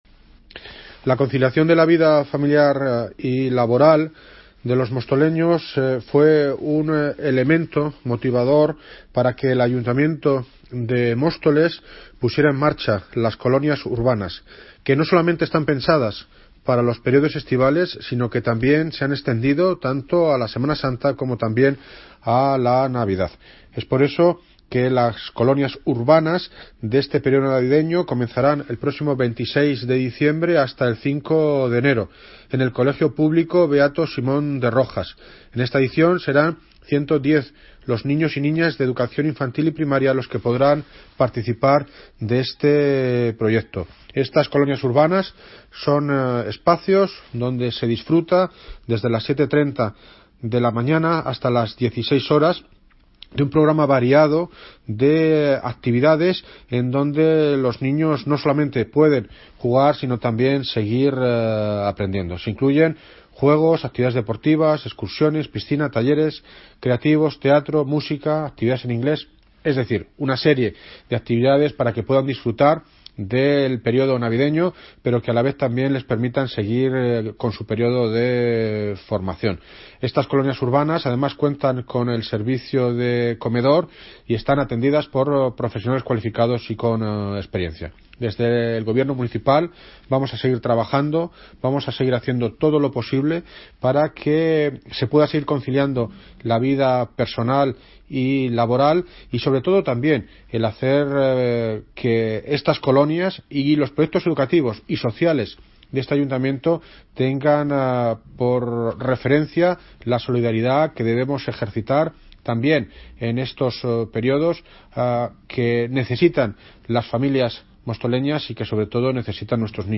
Audio - David Lucas (Alcalde de Móstoles) sobre Colonias Urbanas
Audio - David Lucas (Alcalde de Móstoles) sobre Colonias Urbanas.mp3